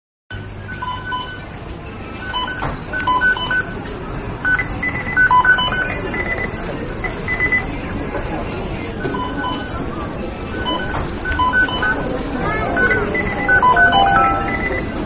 Mcd Beeping Sound Button - Free Download & Play